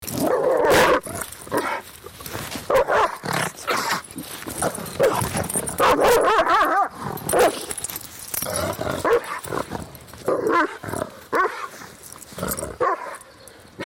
Download Angry Dog sound effect for free.
Angry Dog